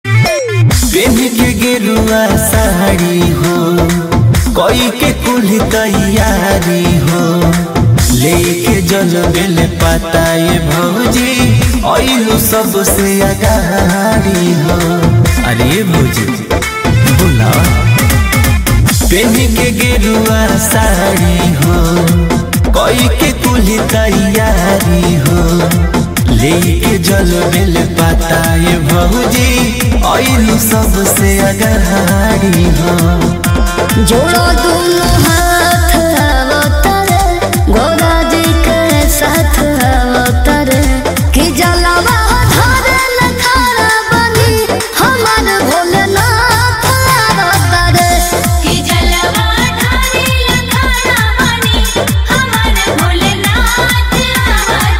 Bhojpuri Bolbum Ringtones